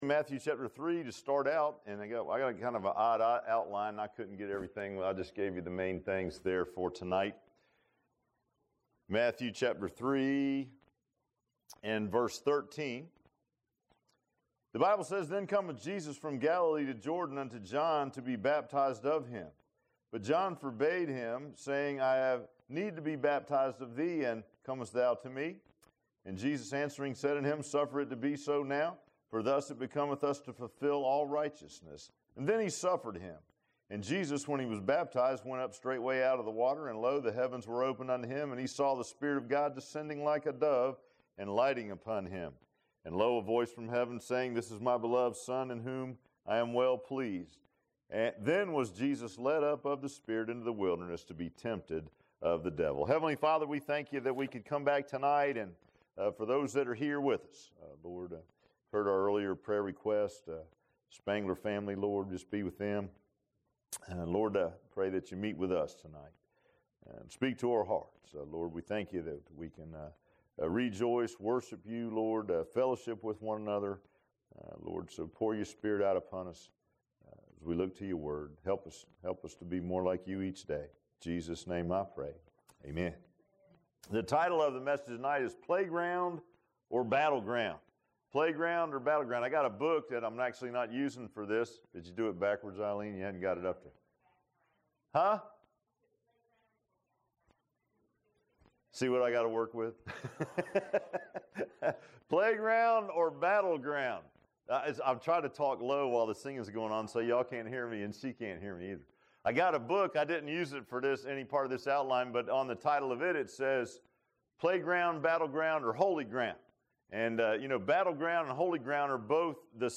Passage: Matthew 3 & Ephesians 6 Service Type: Sunday PM